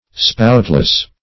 spoutless - definition of spoutless - synonyms, pronunciation, spelling from Free Dictionary
Spoutless \Spout"less\, a. Having no spout.